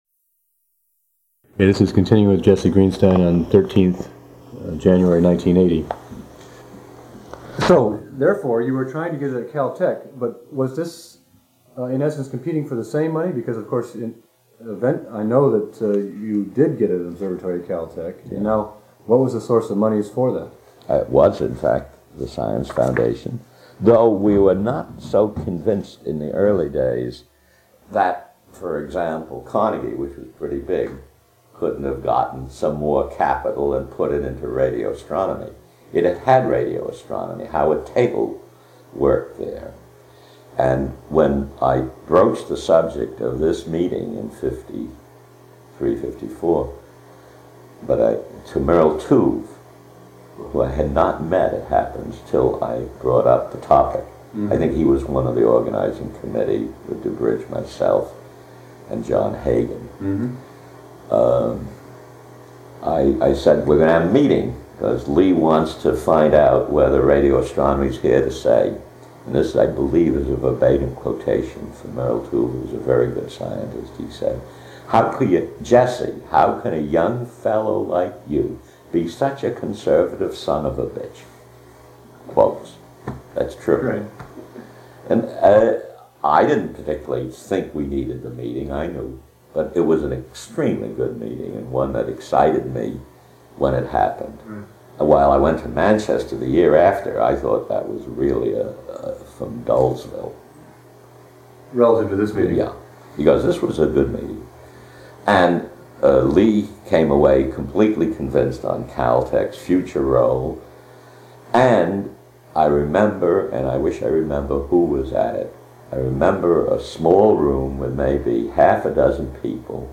Interviewed 13 January 1980 in Pasadena, California, length of interview 115 minutes.
Type Oral History
Original Format of Digital Item Audio cassette tape